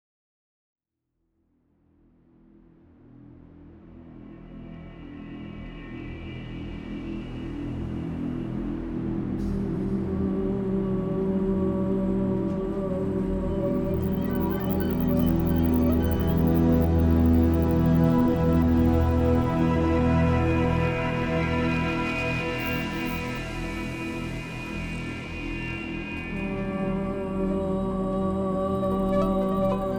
Adventurous Electronic Excursions
Voice with Live Processing
Guitar and SuperCollider